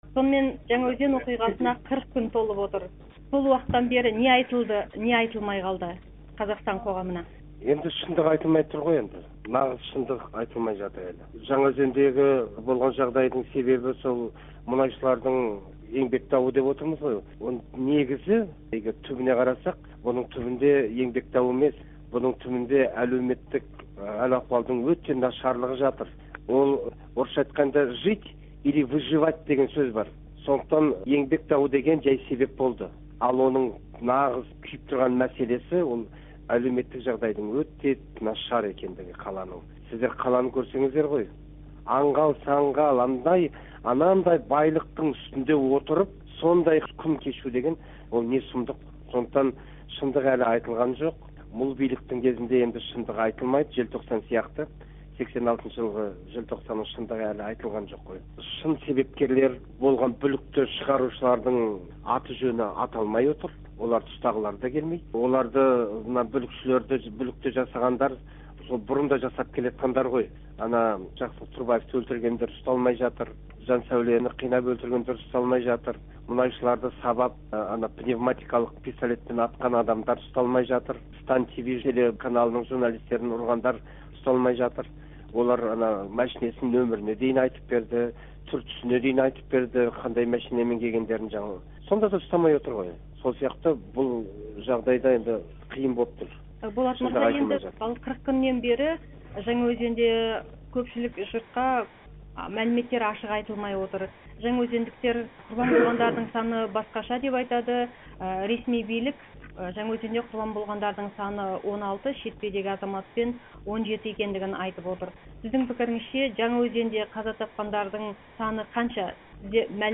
Болат Атабаевпен сұқбатты тыңдаңыз